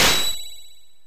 Lock Break